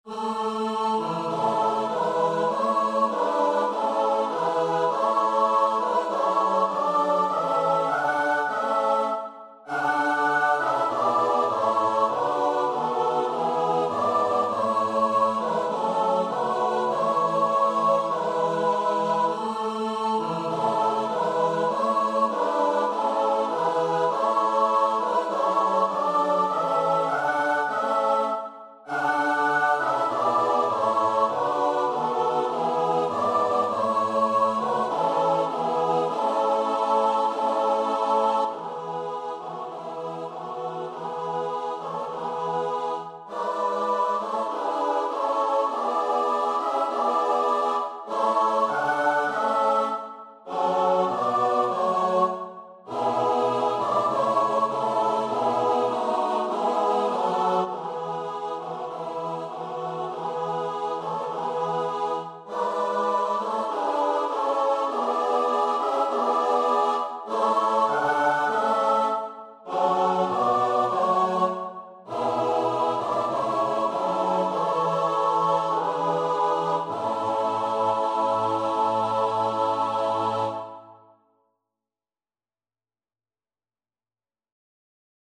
Free Sheet music for Choir (SATB)
A major (Sounding Pitch) (View more A major Music for Choir )
Maestoso
4/4 (View more 4/4 Music)
Choir  (View more Easy Choir Music)
Traditional (View more Traditional Choir Music)